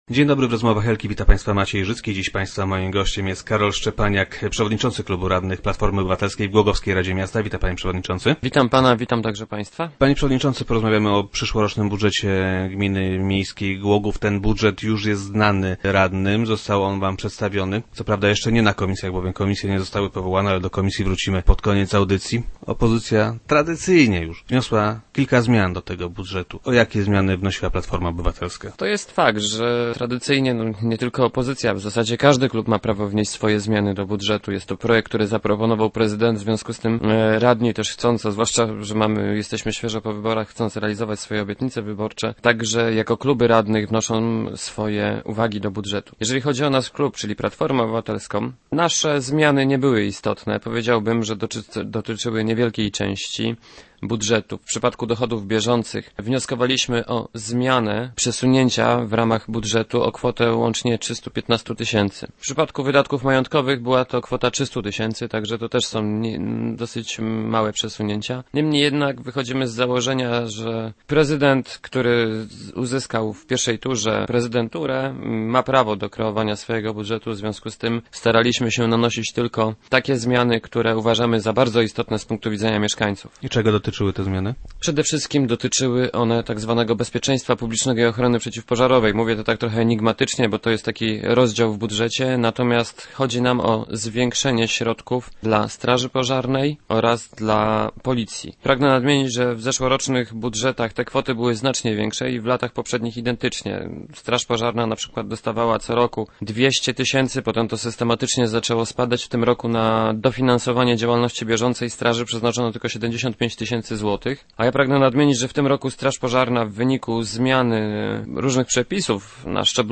Takie zmiany w przyszłorocznym budżecie miasta proponują głogowscy radni Platformy Obywatelskiej. Gościem Rozmów Elki był Karol Szczepaniak, przewodniczący klubu radnych PO.